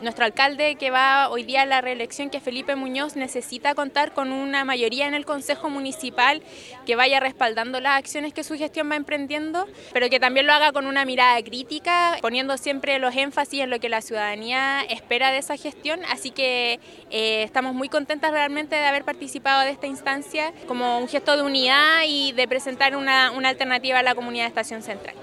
La proclamación se realizó en la Plaza del Cristo, durante la mañana de este sábado ante una gran asistencia de adherentes